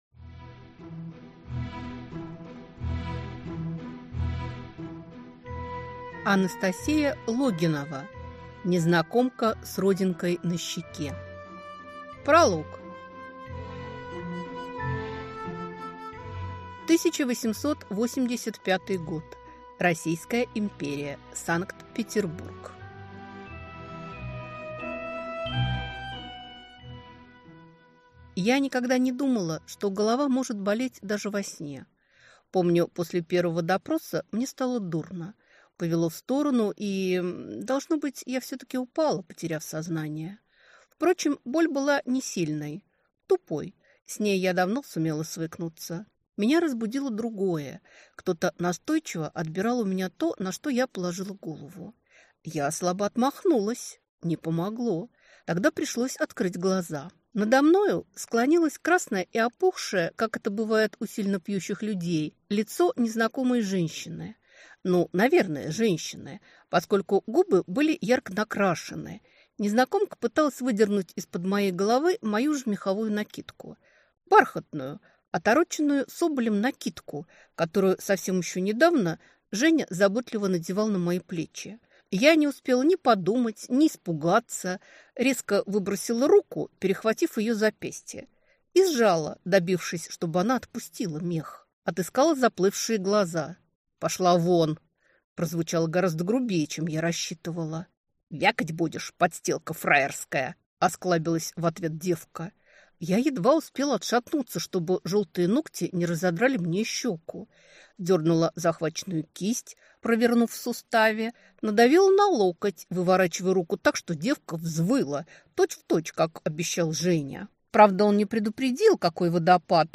Аудиокнига Незнакомка с родинкой на щеке | Библиотека аудиокниг